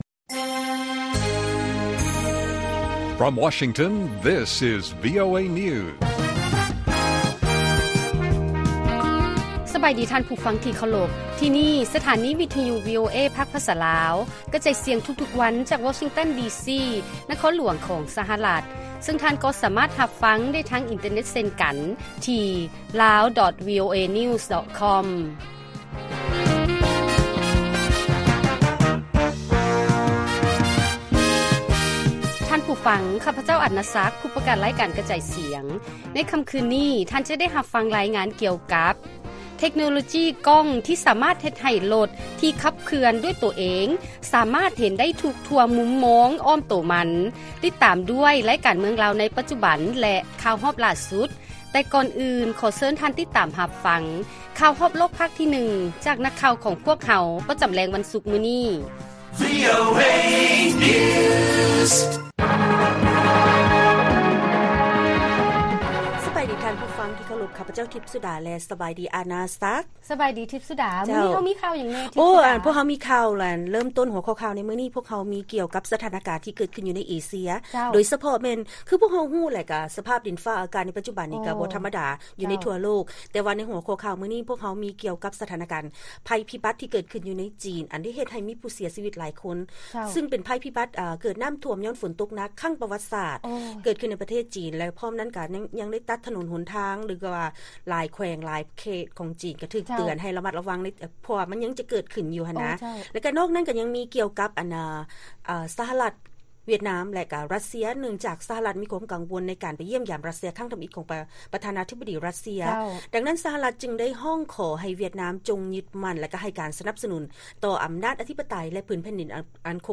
ລາຍການກະຈາຍສຽງຂອງວີໂອເອ ລາວ: ເທັກໂນໂລຈີກ້ອງ ທີ່ສາມາດເຮັດໃຫ້ລົດທີ່ຂັບເຄື່ອນດ້ວຍໂຕເອງສາມາດເຫັນໄດ້ທົ່ວທຸກມຸມ ອ້ອມໂຕມັນໄດ້